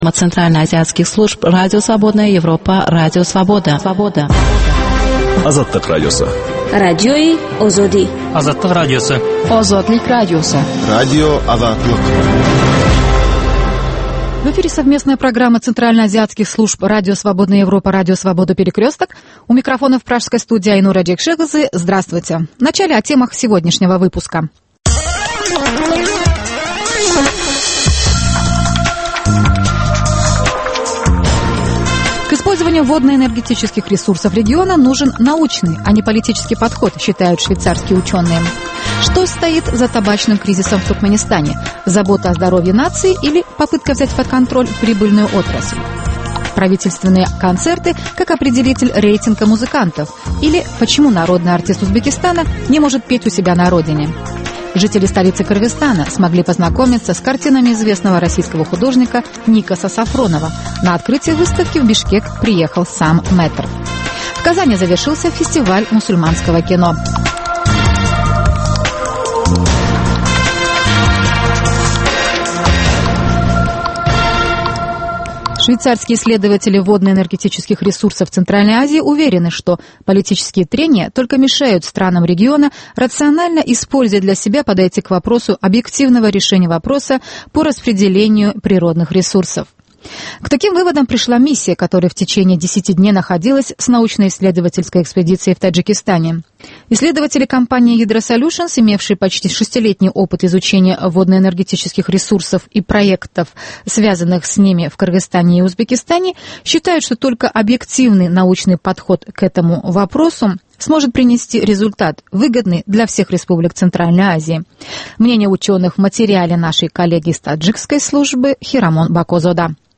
Азаттыктын кабарлары